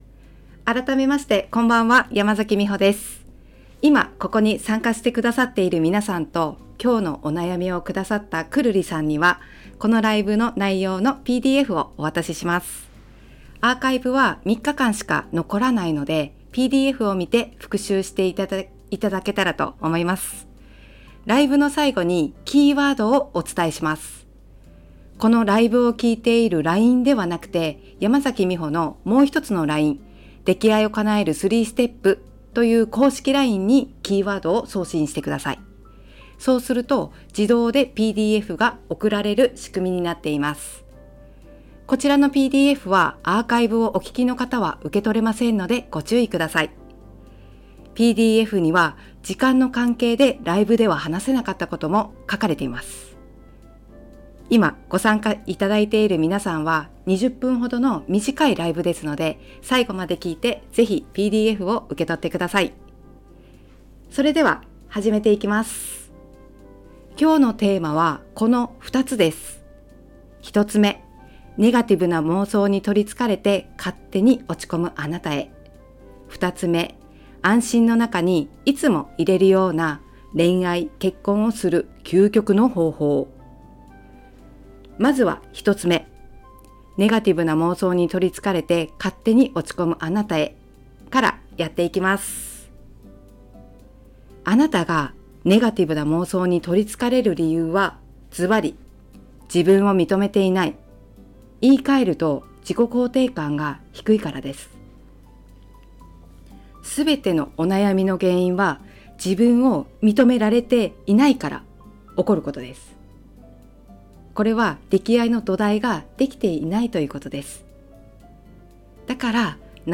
ライブトーク♡25-3-4